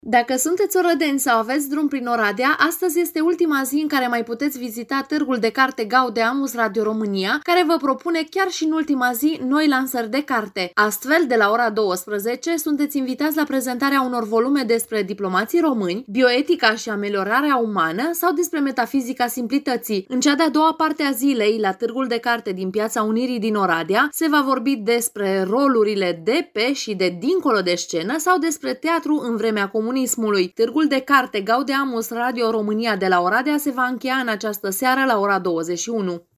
Cum au apreciat orădenii Târgul de la Oradea, aflăm de la unii dintre expozanți și dintre vizitatori: